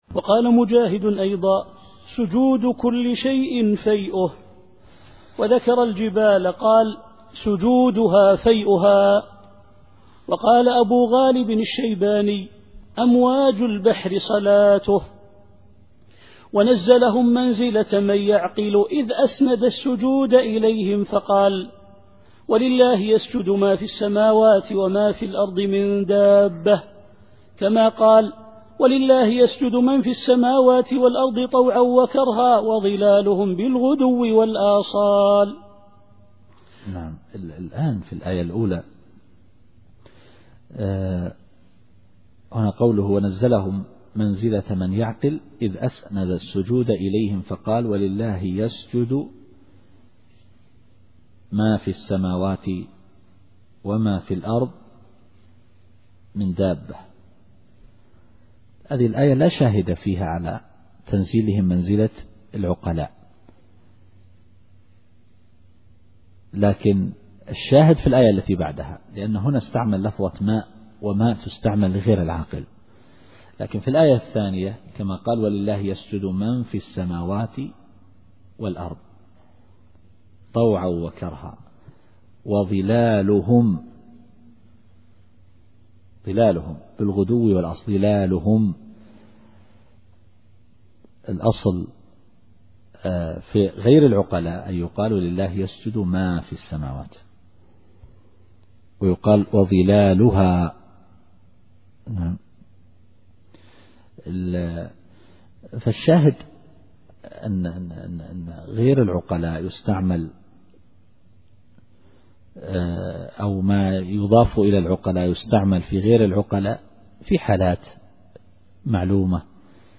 التفسير الصوتي [النحل / 49]